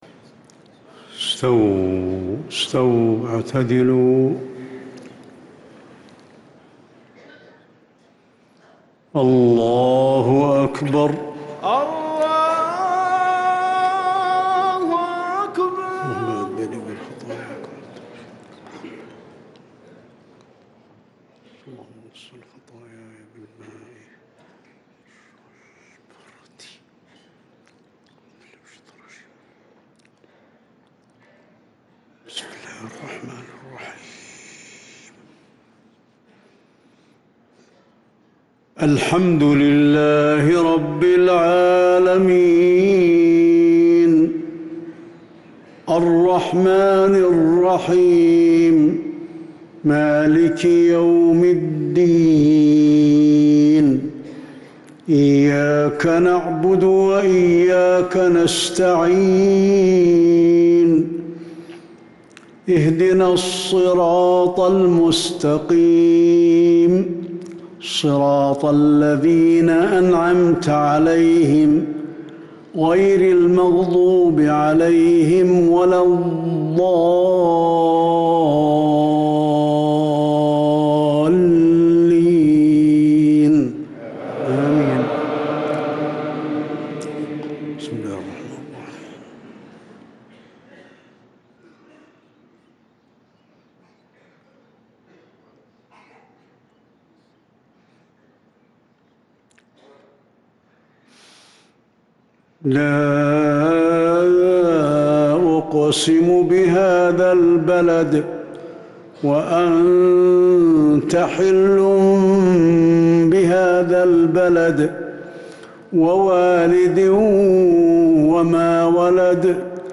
صلاة المغرب للقارئ علي الحذيفي 16 ربيع الأول 1444 هـ
تِلَاوَات الْحَرَمَيْن .